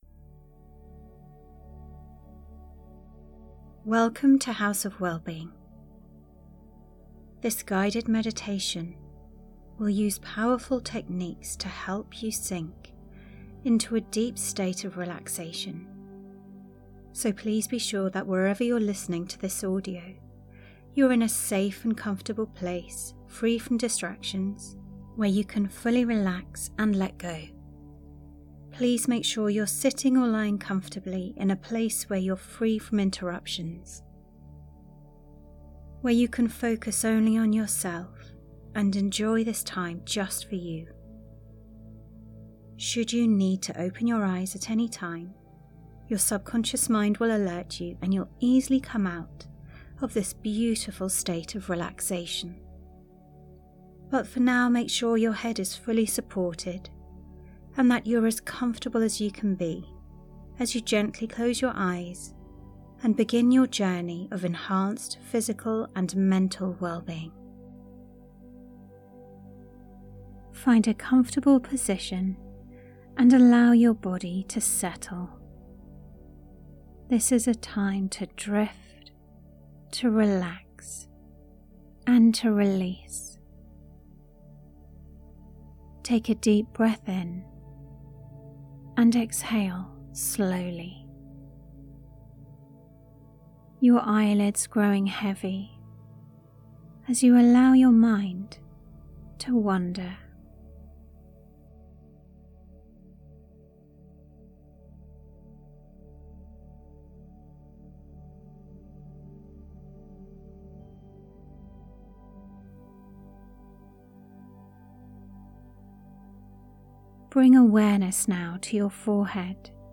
This Better Sleep calming session helps quiet the mind and relax the body for deep, restful sleep. By gently activating points on the forehead, chest, wrists, and feet, you release overthinking, ease tension, and ground yourself into safety and stillness, allowing sleep to naturally arrive.